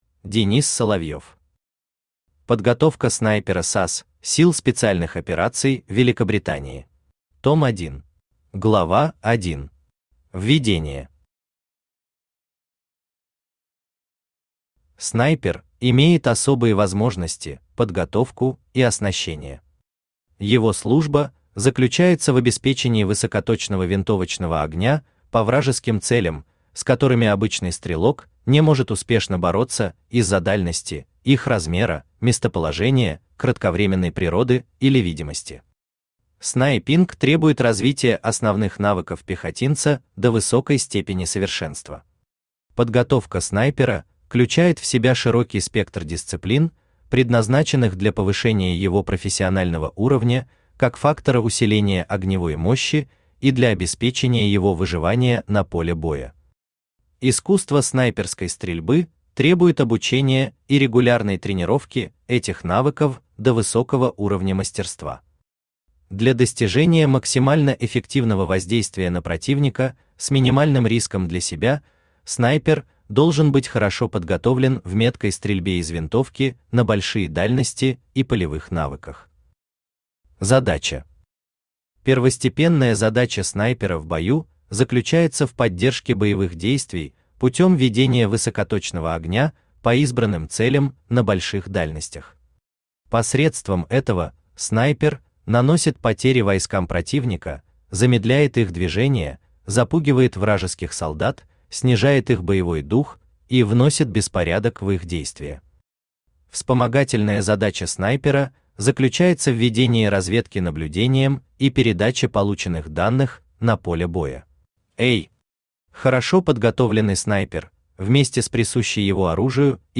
Аудиокнига Подготовка снайпера САС (сил специальных операций) Великобритании. Том 1 | Библиотека аудиокниг
Том 1 Автор Денис Соловьев Читает аудиокнигу Авточтец ЛитРес.